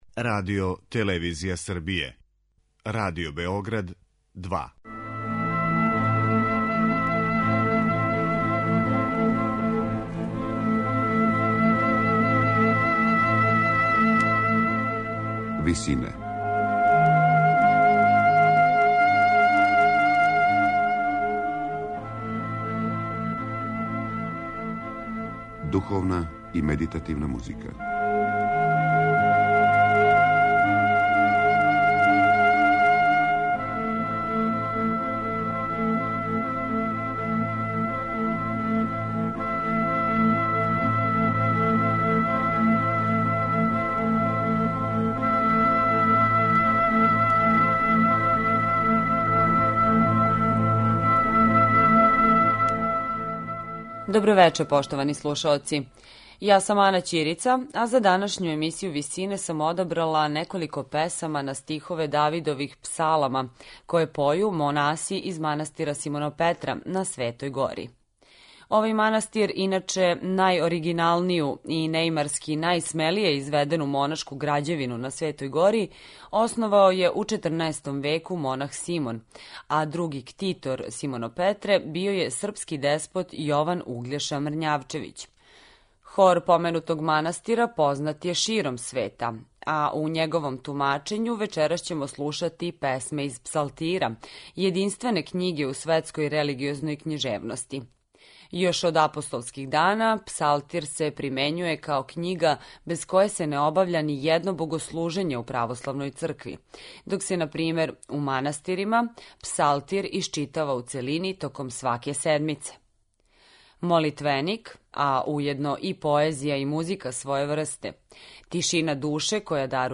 Из Псалтира: Поју монаси из манастира Симонопетра на Светој Гори
На Велики понедељак, слушаћете песме из Псалтира које поју монаси из манастира Симонопетра на Светој Гори.
медитативне и духовне композиције